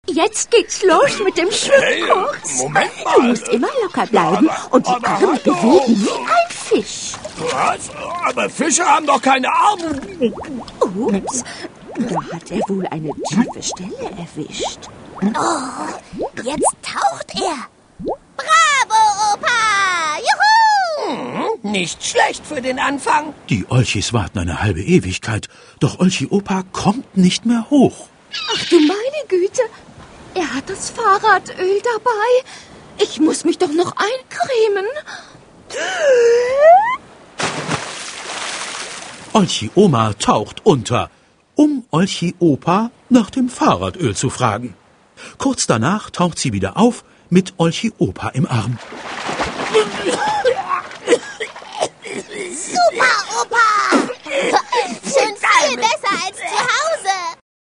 Szenische Lesung
Der erste Band der Kultserie als szenische Lesung mit den beliebten Olchi-Sprechern.